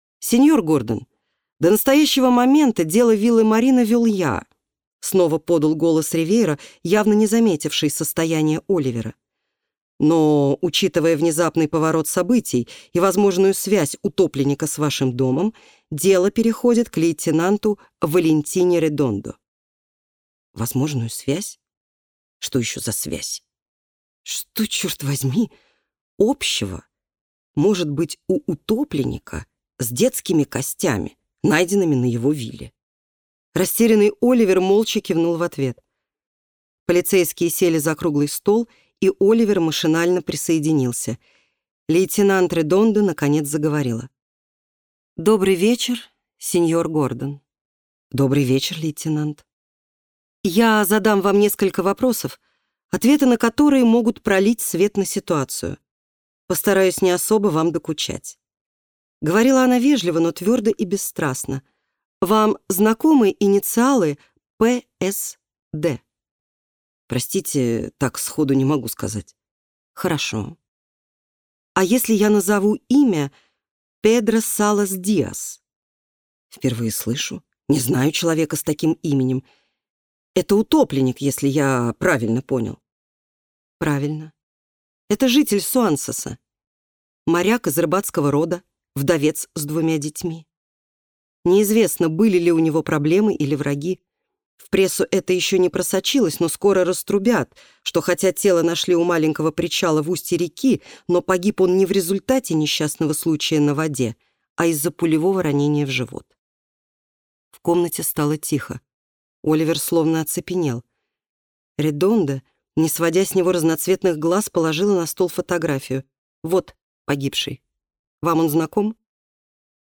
Аудиокнига Скрытая бухта | Библиотека аудиокниг